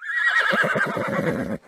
animalia_horse_idle.1.ogg